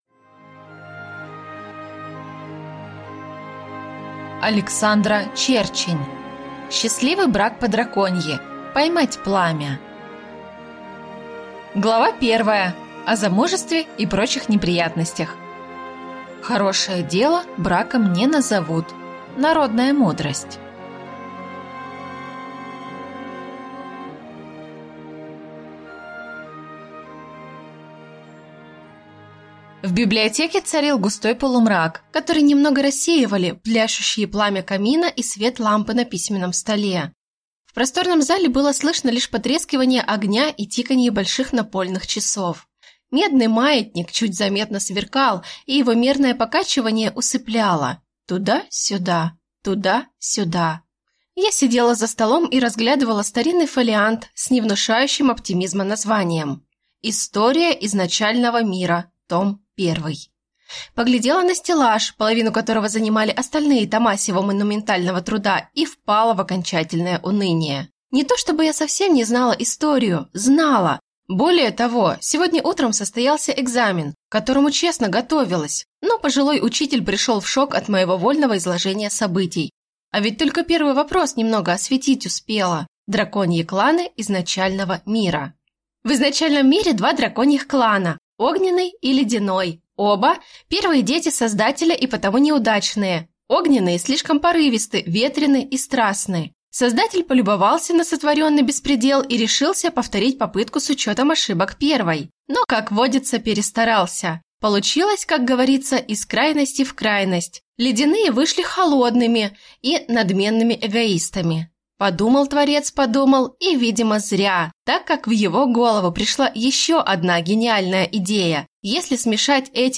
Аудиокнига Счастливый брак по-драконьи. Поймать пламя - купить, скачать и слушать онлайн | КнигоПоиск